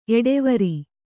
♪ eḍevari